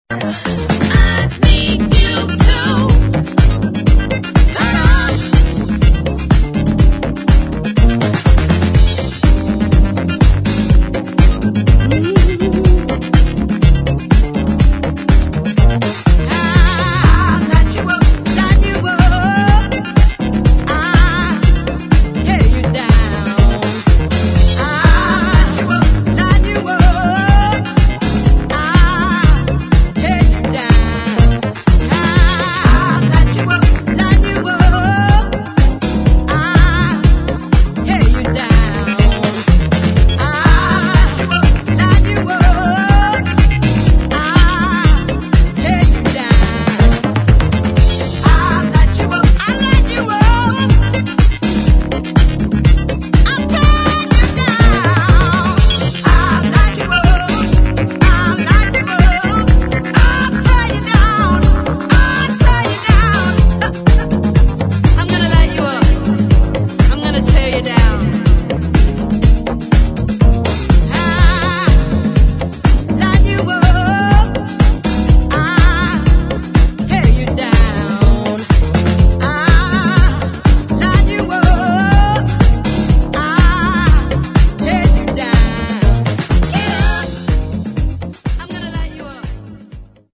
[ DEEP HOUSE | DISCO ]